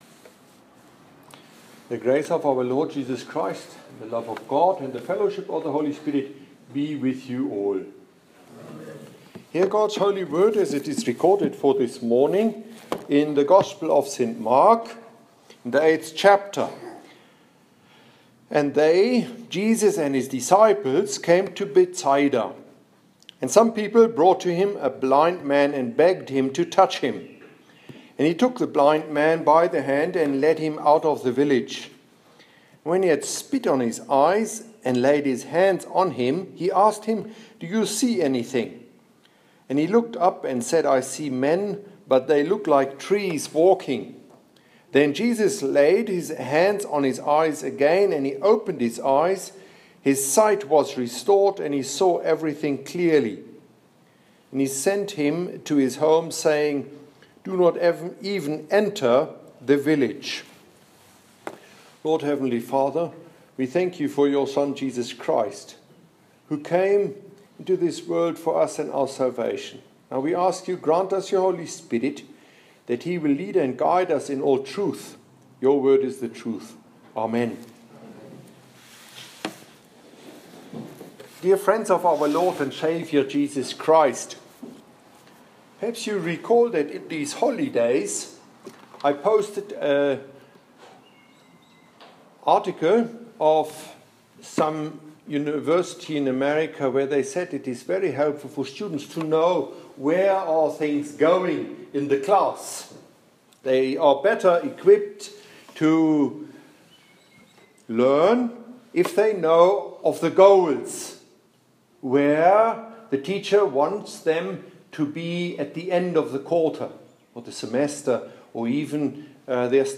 Here's the audio file of the sermon preached this morning during Matins in the chapel of St.Timothy at the Lutheran Theological Seminary: